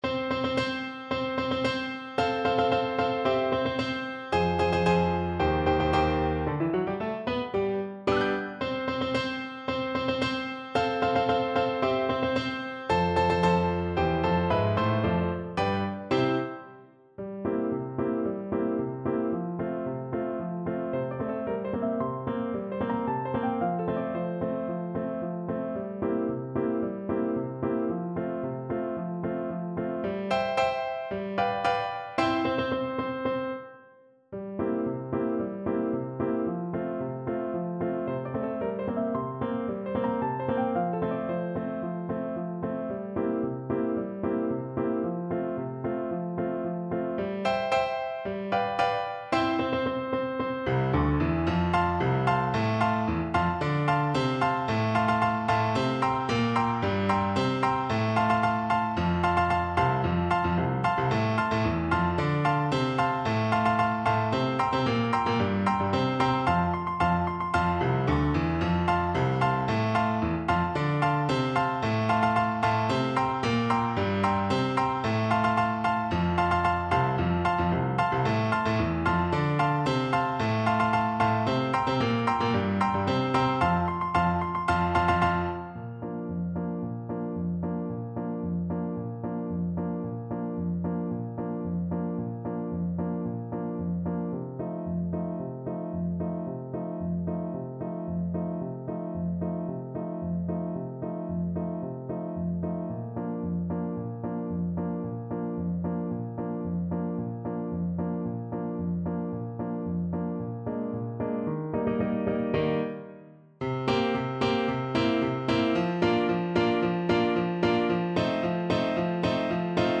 2/4 (View more 2/4 Music)
~ = 112 Introduction
Classical (View more Classical French Horn Music)